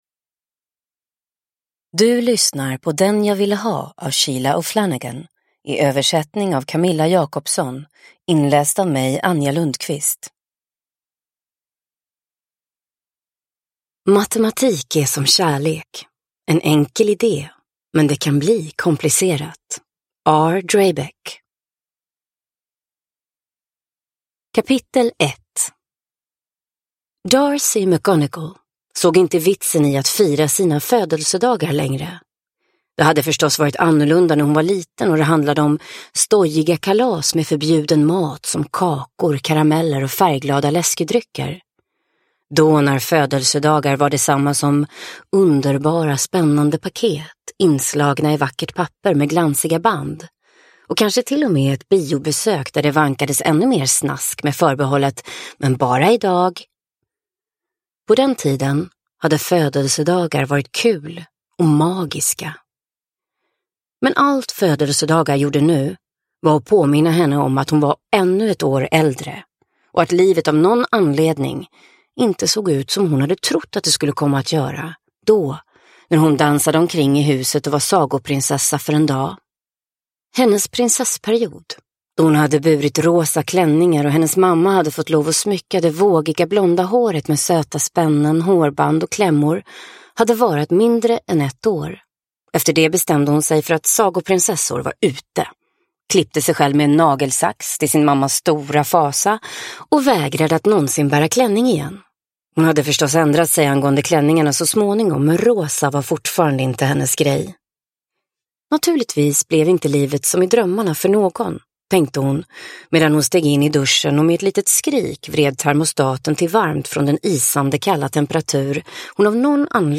Den jag ville ha – Ljudbok – Laddas ner
Uppläsare: Anja Lundqvist